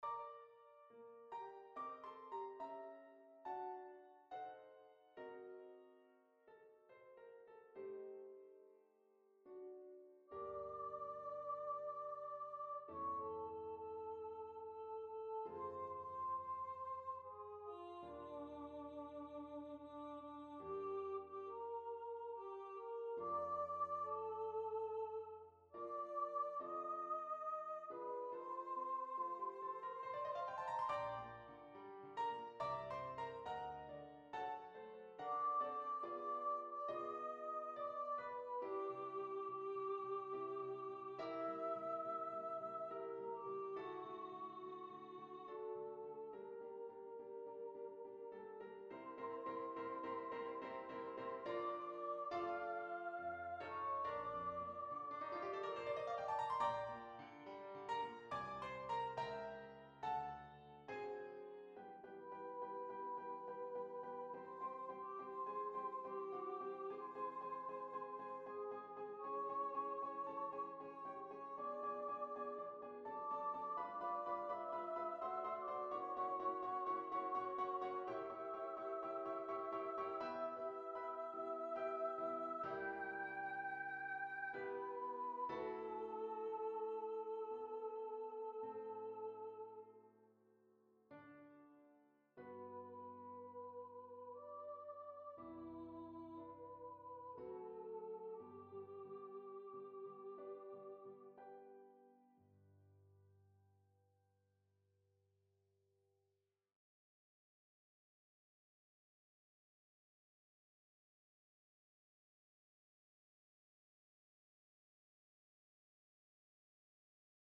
Voices: Solo Voice Instrumentation: Piano